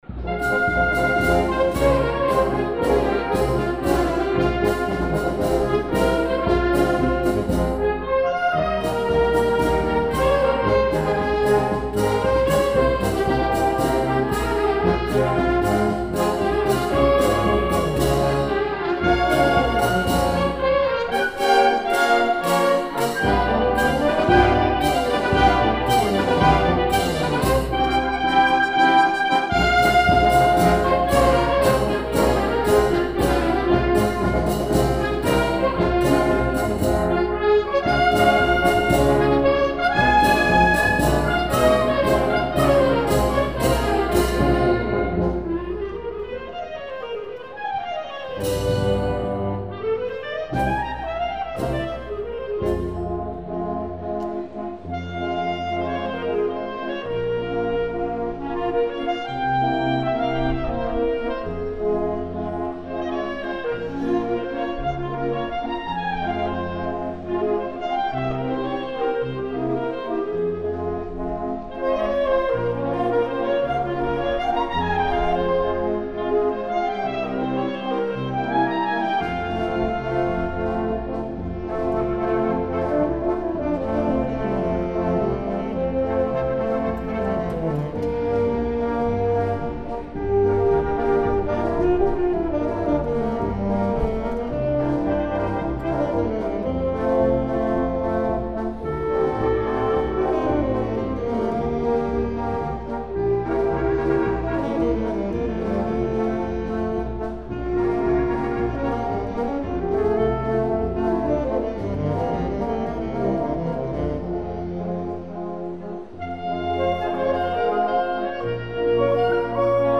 Marcia Sinfonica